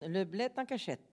Localisation Sallertaine
Enquête Arexcpo en Vendée
Catégorie Locution